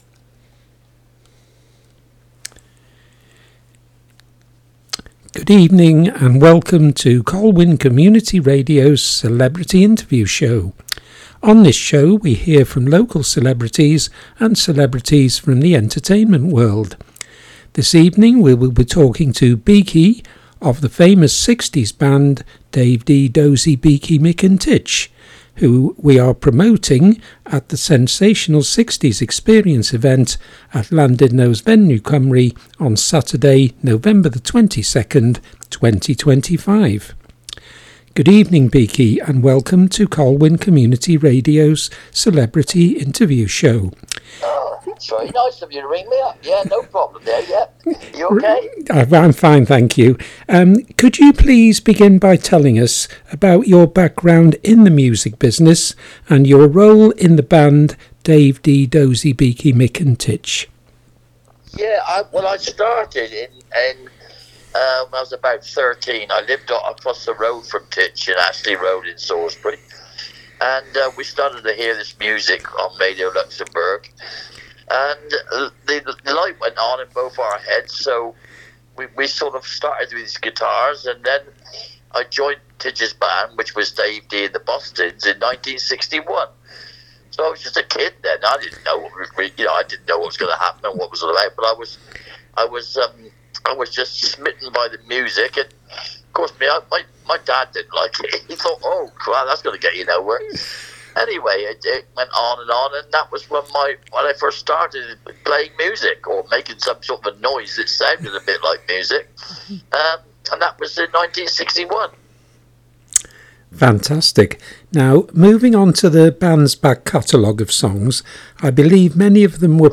Celebrity Interviews.
Interview with Beaky from Dozy, Beaky Mick and Tich, for The Sensational 60s Experience Show, Llandudno’s Venue Cymru, Saturday, 22nd November, 2025.